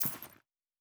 Coin and Purse 05.wav